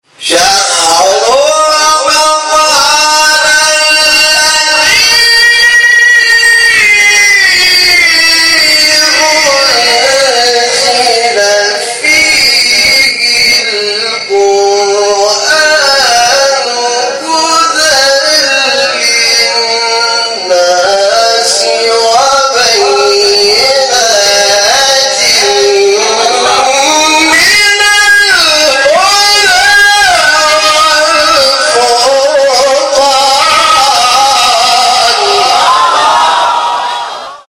آیه 185 شهر رمضان سوره بقره استاد شاکرنژاد مقام نهاوند | نغمات قرآن | دانلود تلاوت قرآن